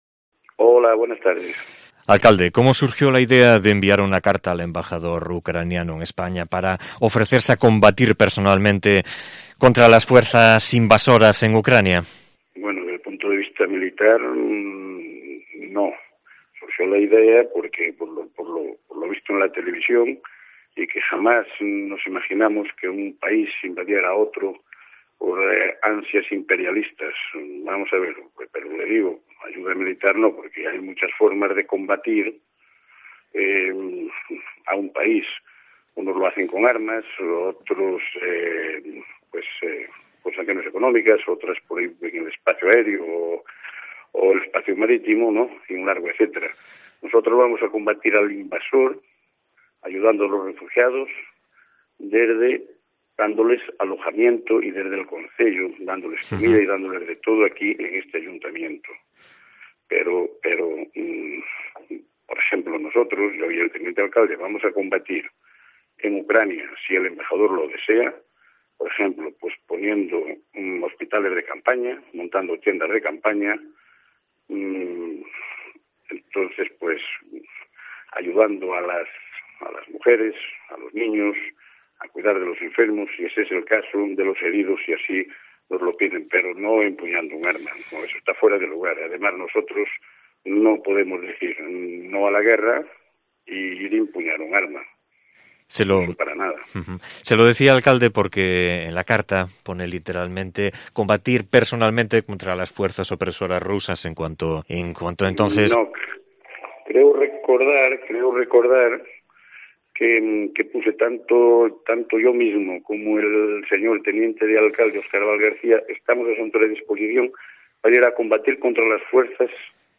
En la carta "no nos referíamos a coger el fusil, en absoluto. Eso estaría fuera de lugar", añade en conversación con Cope.